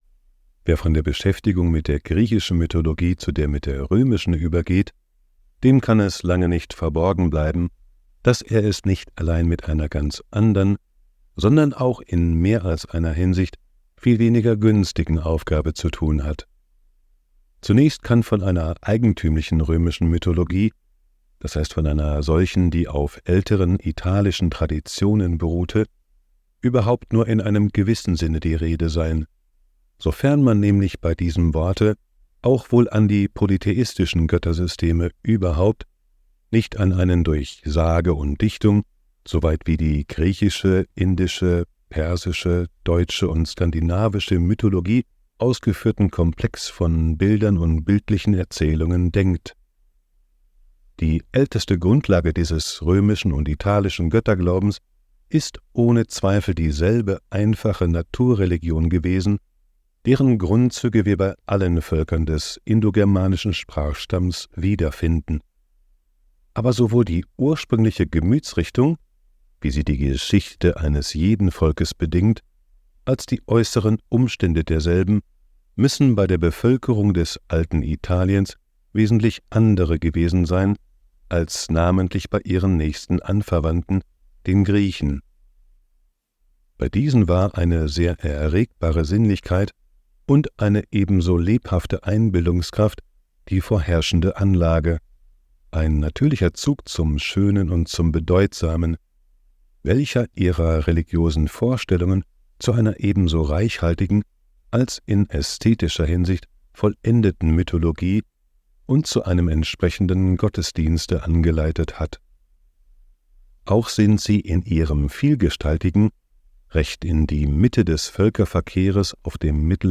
Griechische Götter-Schlummer - Mythen, die deine Nacht verzaubern (Hörbuch)